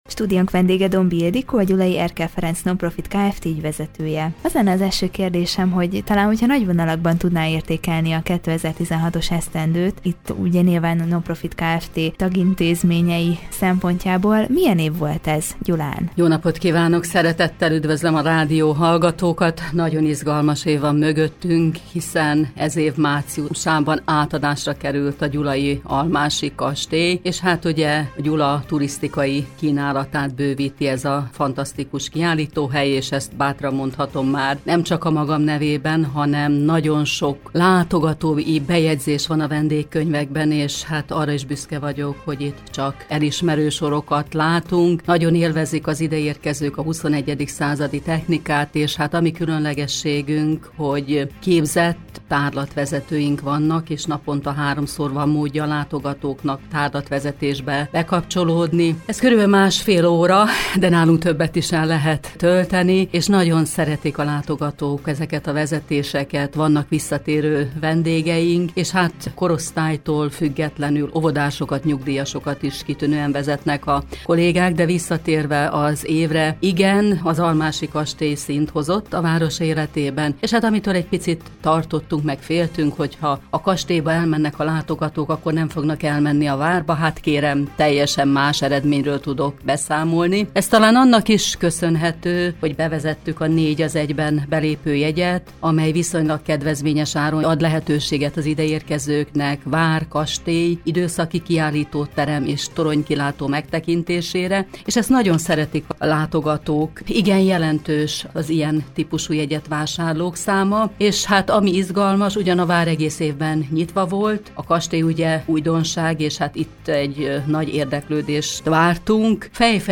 Évértékelő interjújában kiemelte, hogy nagyon izgalmas volt a 2016-os esztendő.